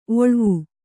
♪ oḷvu